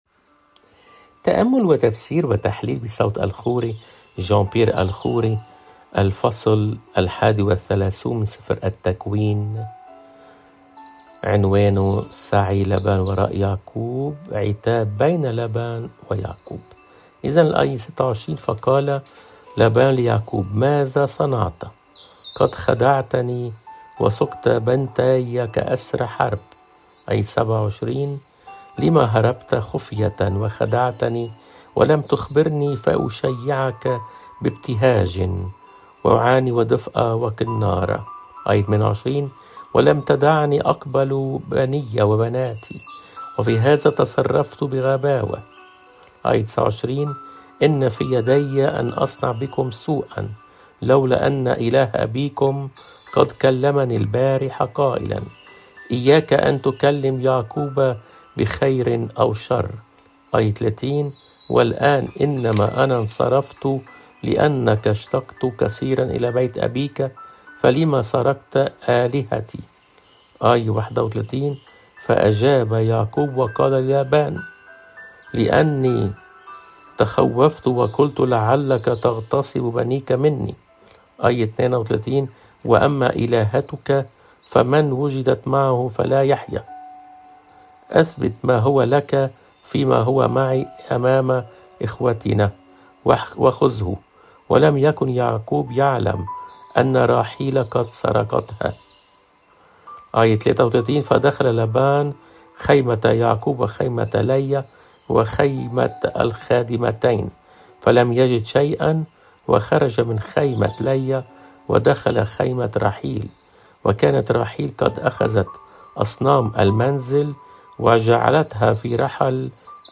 تفسير العهد القديم